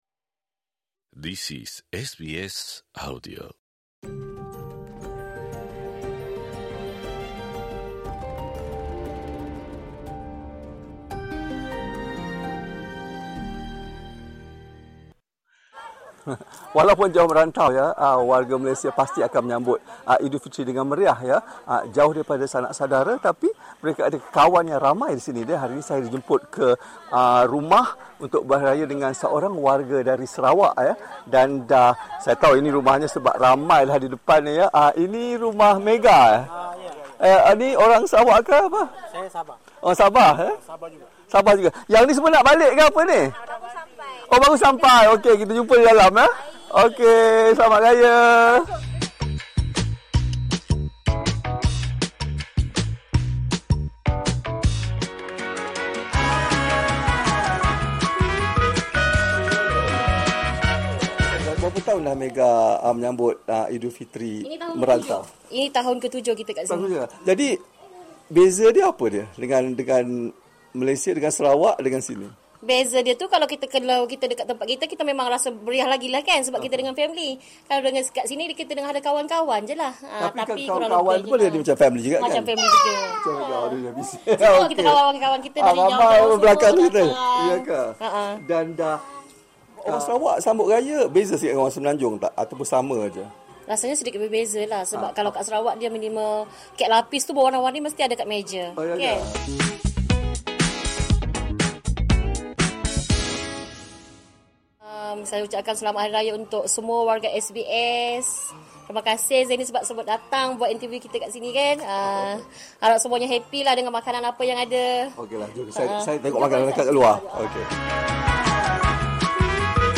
di rumah terbuka sempena sambutan Eidul Fitri